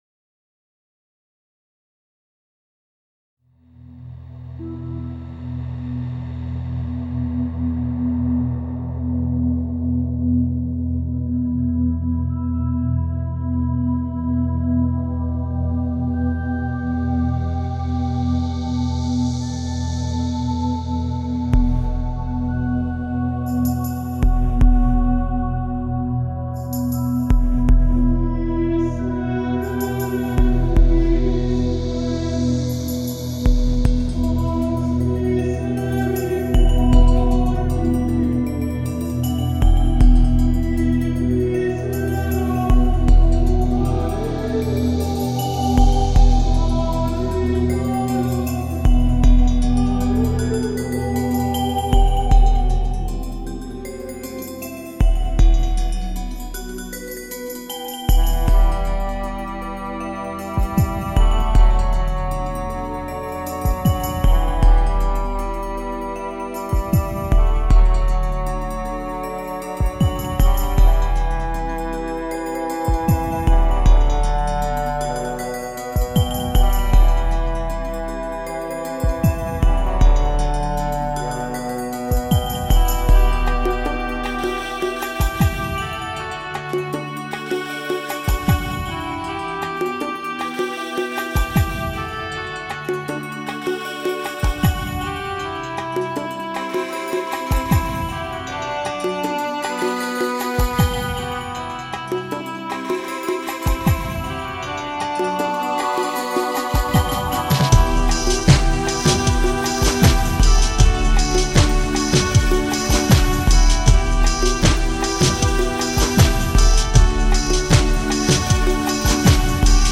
Genre: New Age.